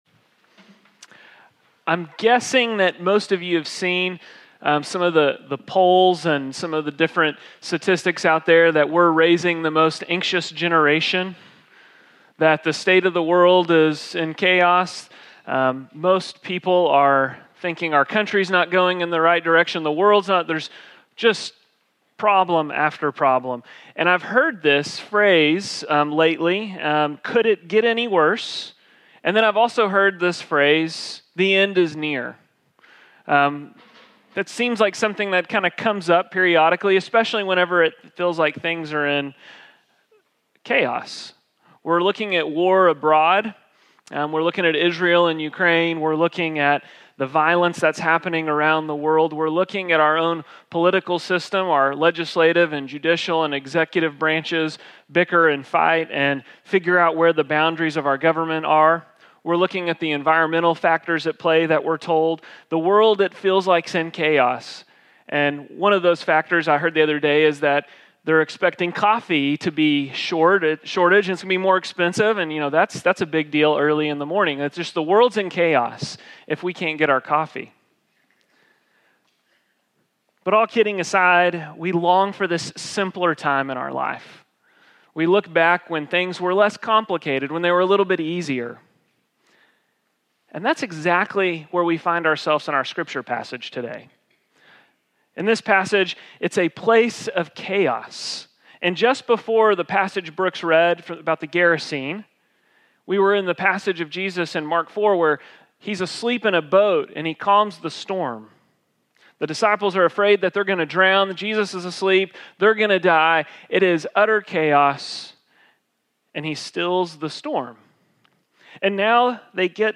Sermon Audio Archive